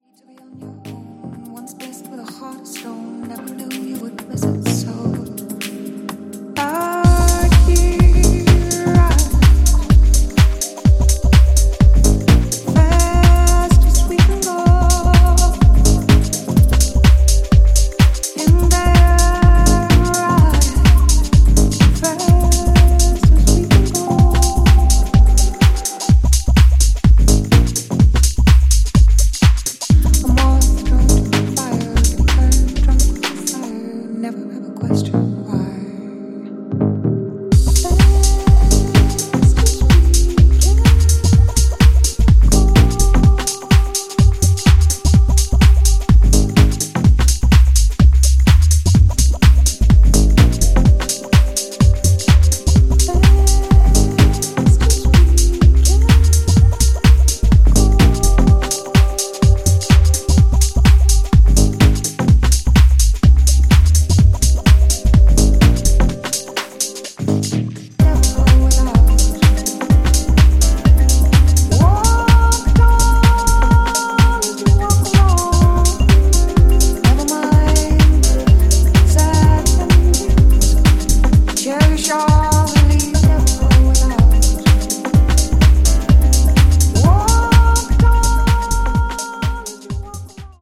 > HOUSE・TECHNO
ジャンル(スタイル) DEEP HOUSE